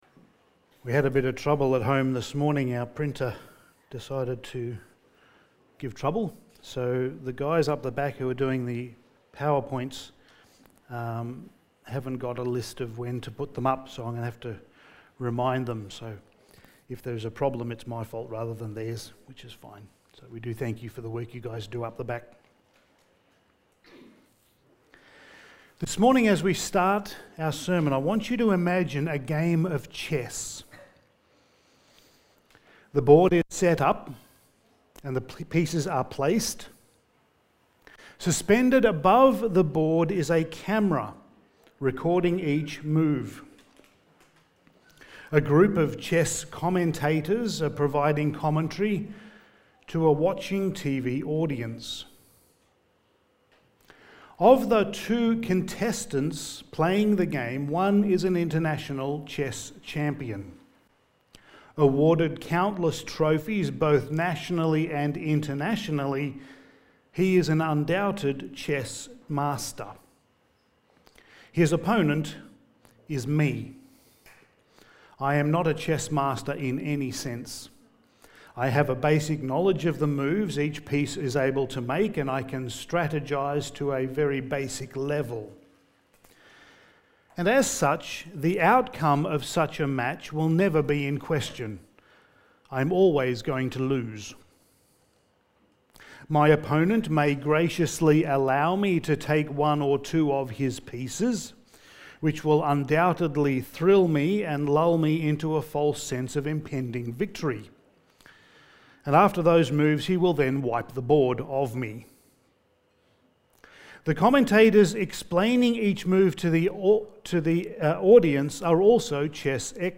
Passage: Daniel 2:1-16 Service Type: Sunday Morning